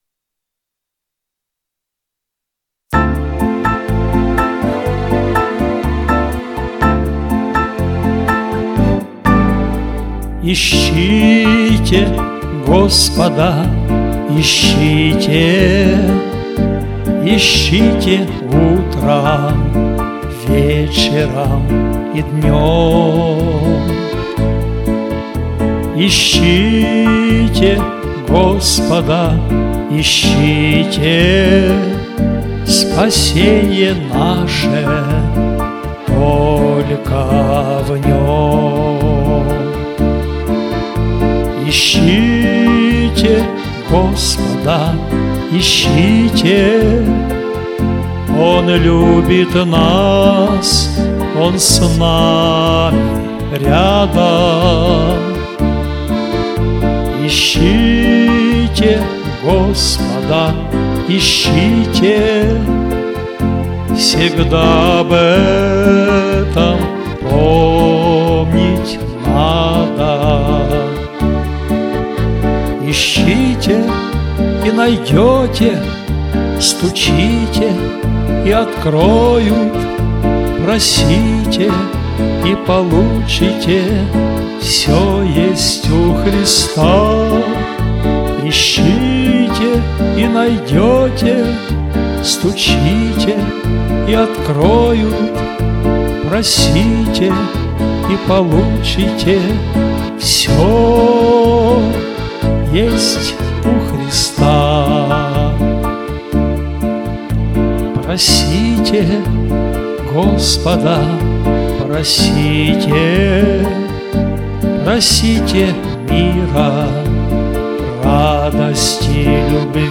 Главная » Файлы » Авторские песни.
Христианские песни